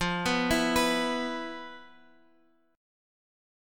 E5/F Chord